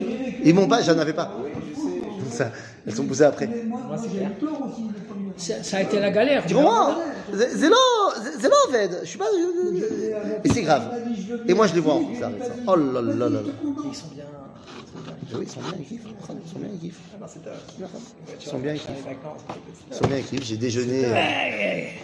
שיעור מ 06 יוני 2023 59MIN הורדה בקובץ אודיו MP3 (345.35 Ko) הורדה בקובץ וידאו MP4 (3.07 Mo) TAGS : שיעורים קצרים